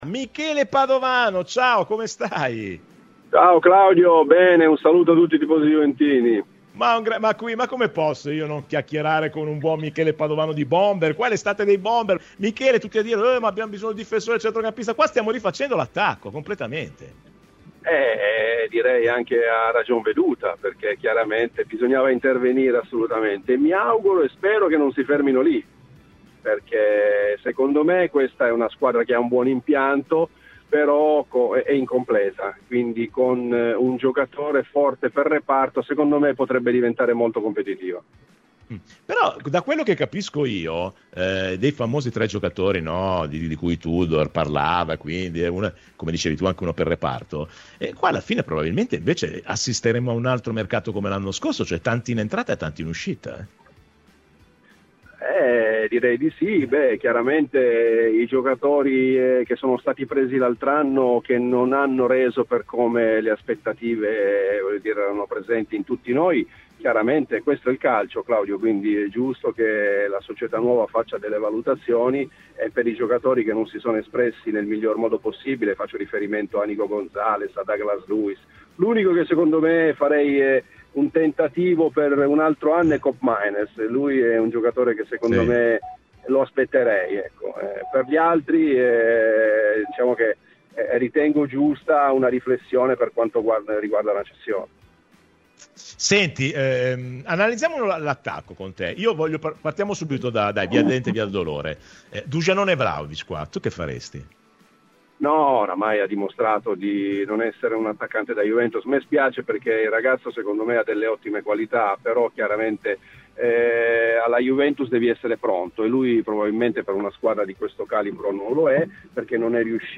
Michele Padovano è intervenuto in esclusiva a Radio Bianconera. L'ex attaccante della Juve, ha parlato durante la trasmissione Rassegna Stramba.